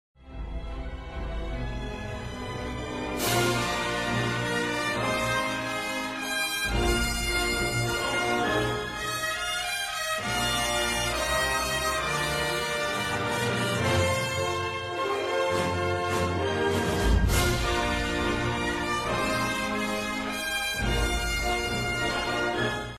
USS Enterprise NCC 1701 refit sound effects free download
USS Enterprise NCC 1701 refit fly by.